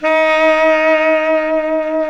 Index of /90_sSampleCDs/Roland LCDP07 Super Sax/SAX_Baritone Sax/SAX_40s Baritone
SAX B.SAX 0C.wav